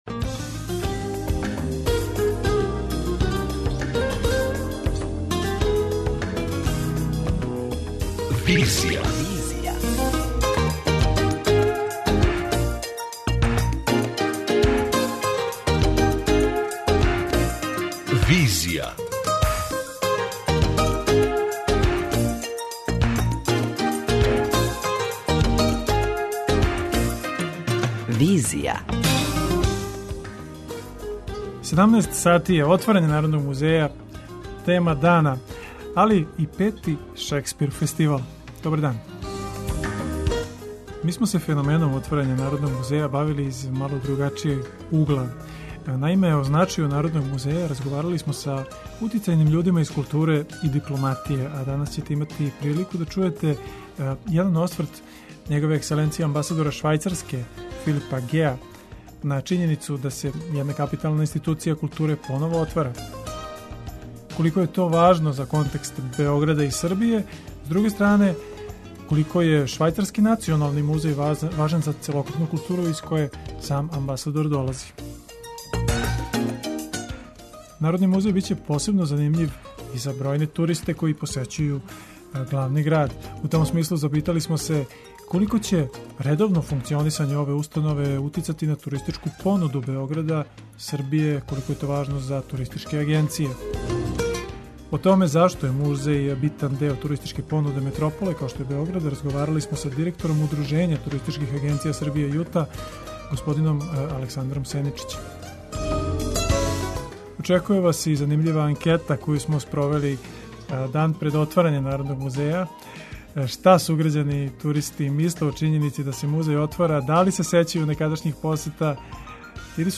Очекује вас и занимљива анкета коју смо спровели дан пред отварање Народног музеја.